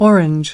4.Orange /ˈɔːr.ɪndʒ/ : quả cam